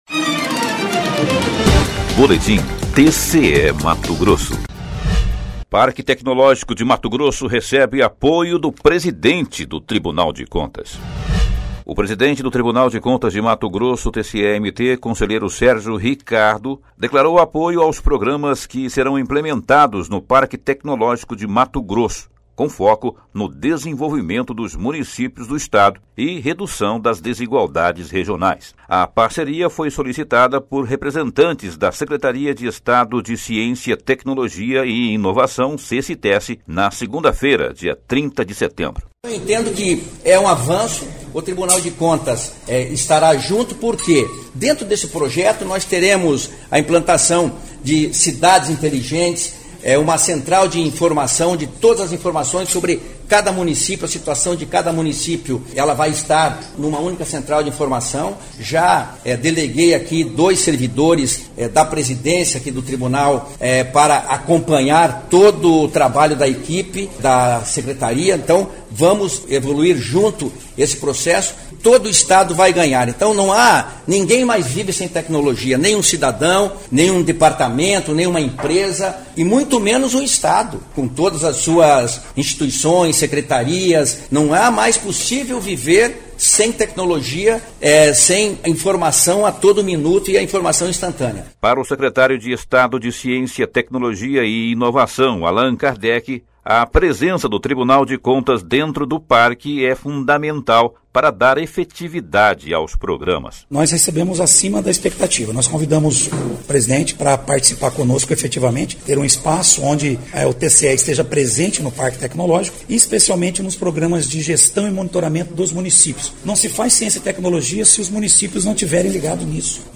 Sonora: Sérgio Ricardo – conselheiro-presidente do TCE-MT
Sonora: Allan Kardec - secretário de Estado de Ciência, Tecnologia e Inovação